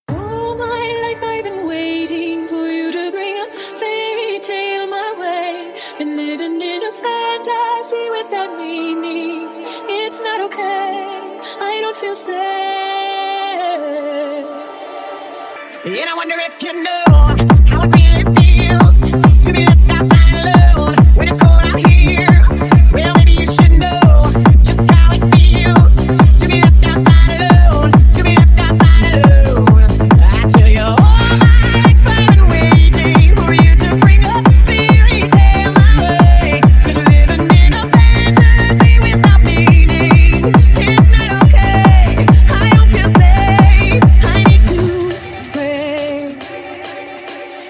Танцевальные рингтоны
Рингтоны ремиксы
Рингтоны техно
клубная музыка